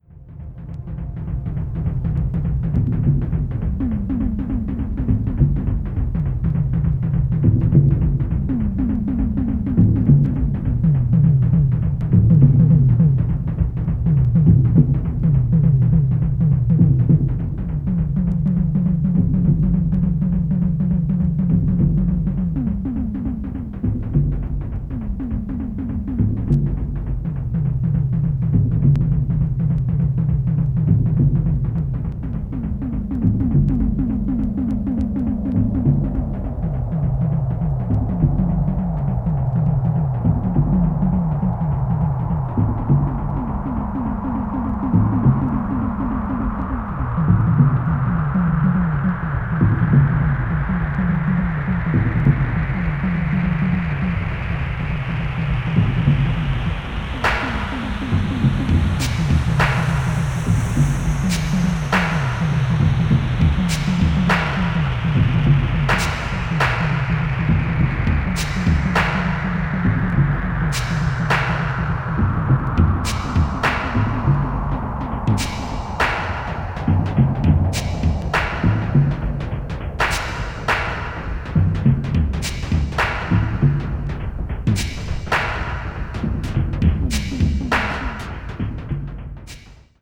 media : EX/EX(わずかにチリノイズが入る箇所あり)
地下のマグマが沸騰しているような、あるいは地球が振動しているような、深い瞑想的な世界です。